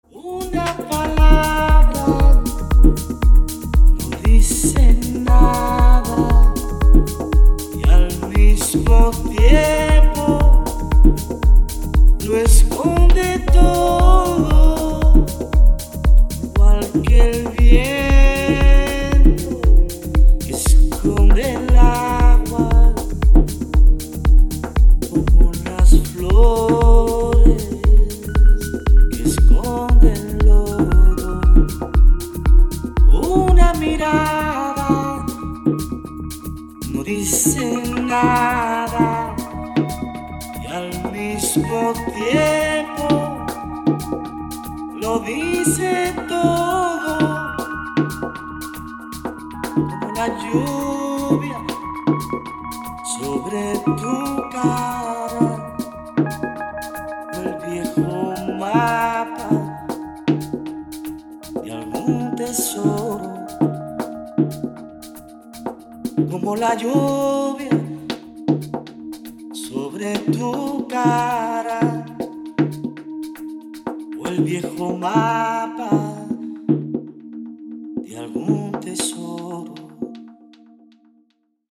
• Качество: 192, Stereo
мужской вокал
Electronic
электронная музыка
спокойные
house
этнические